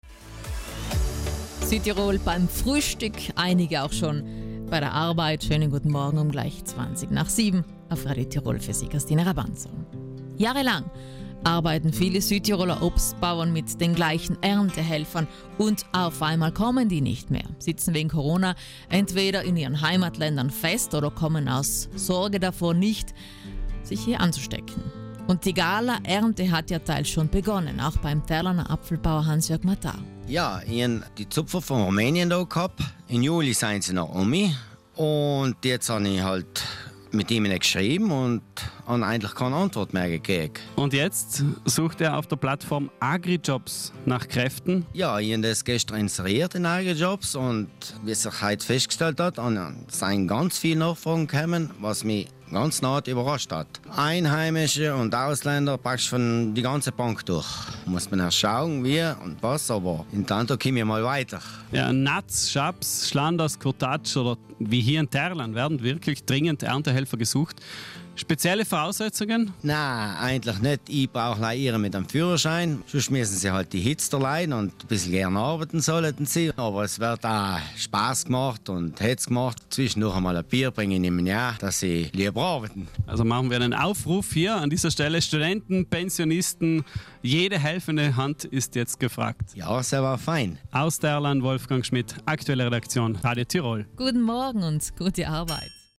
Denn die Händler und ihre Kunden wollen Äpfel haben. In Italiens erster Versteigerung in Sigmundskron herrscht in der Früh bereits Hektik.